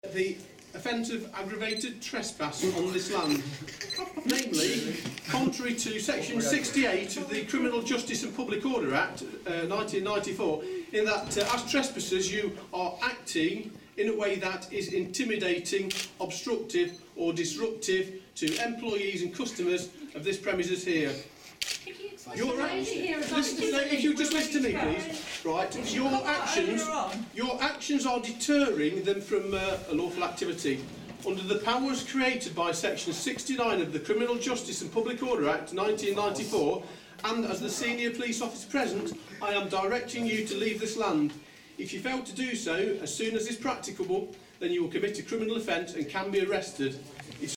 On arrival at the office, people gathered arround the entrance, displaying banners and handing out leaflets.
After talking to Atos management, then proceeded to read out the section of the Criminal Justice and Public Order Act 1994, concerning 'aggrevated tresspass'.